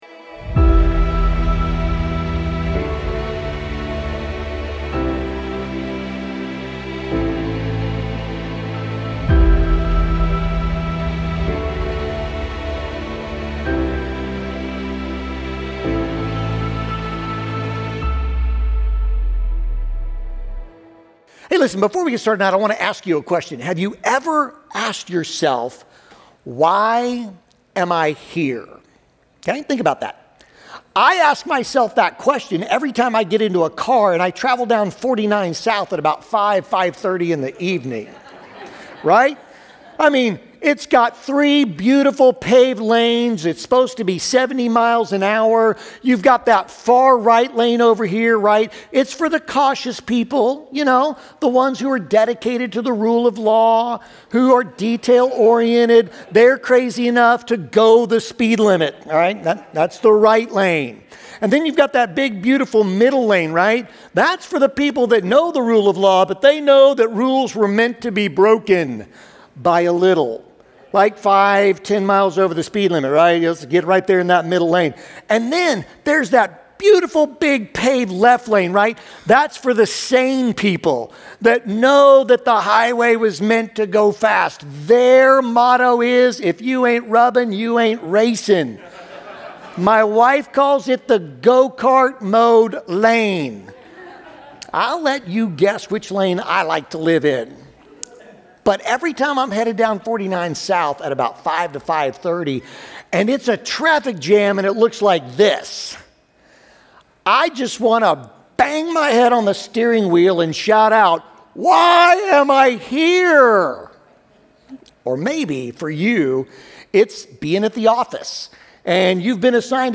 Join us as we begin our new sermon series, Parables: Stories That Changed the World.